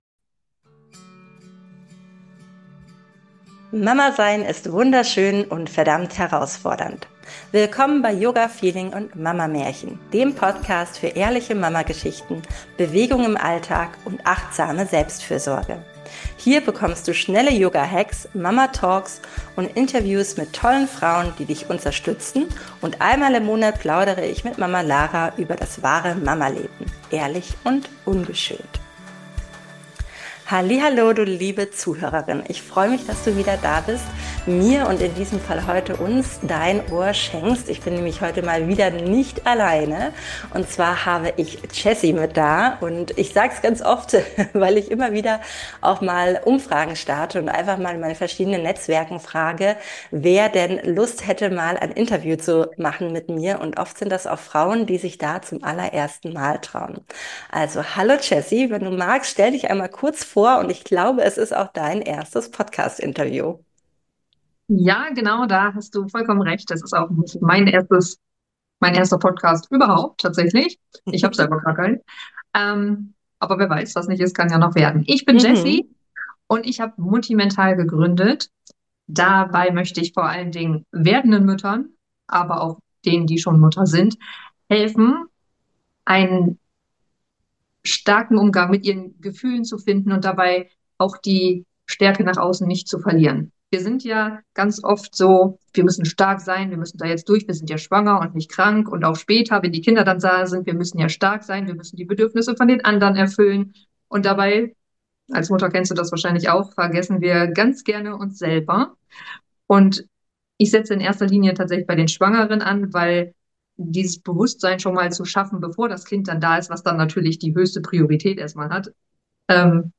Ein ehrliches Gespräch